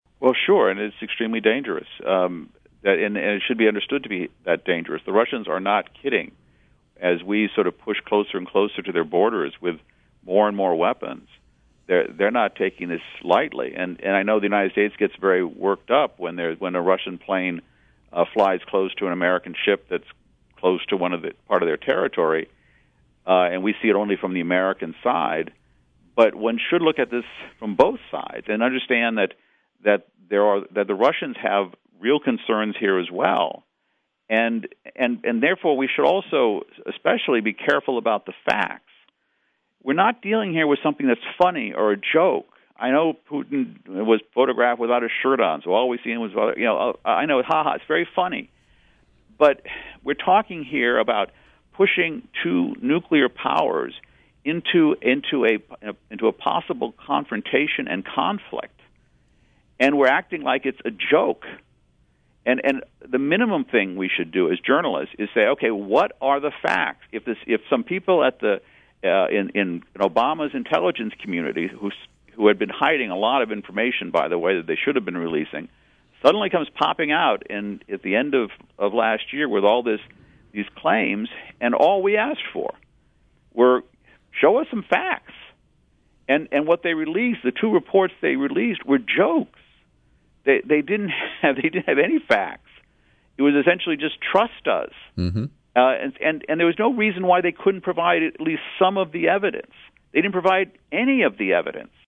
In-Depth Interview: Journalist Robert Parry Challenges Corporate Media Over Fact-Free, Leak-Based Reporting